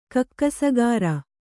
♪ kakkasagāra